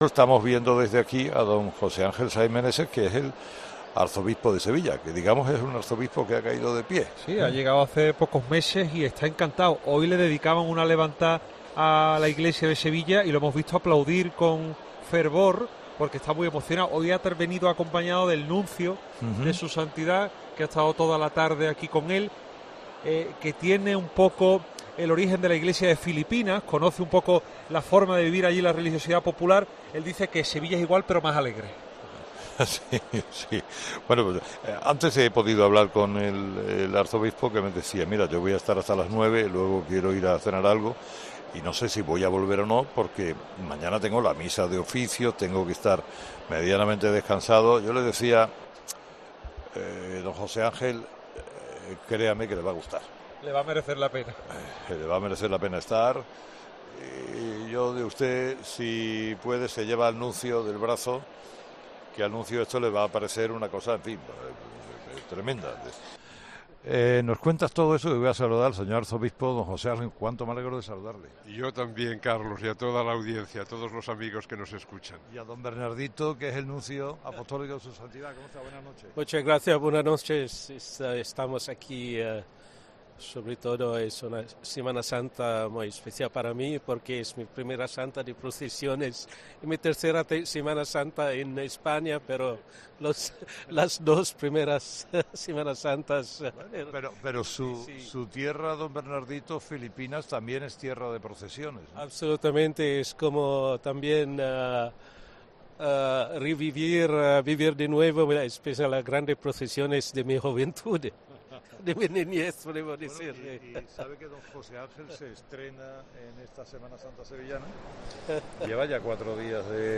Saiz Meneses acompañado por el Nuncio Apostólico en España, Bernardito Auza han conversado con Carlos Herrera en la plaza de la Campana